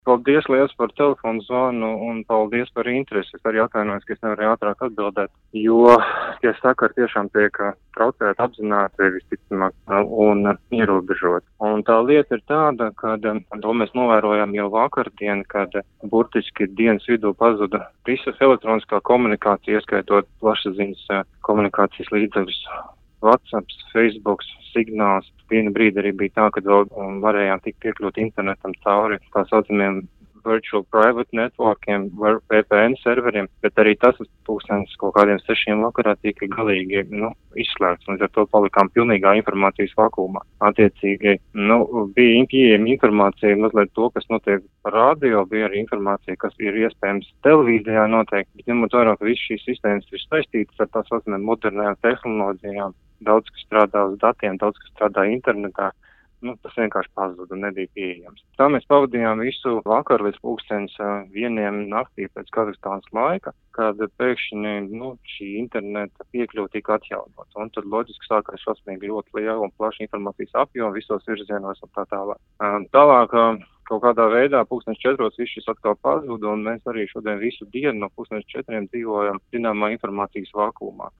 Par situāciju valstī “Radio Skonto” sazinājās ar Latvijas Republikas vēstniecības Kazahstānā vadītāja pienākumu izpildītāju Renāru Vildi.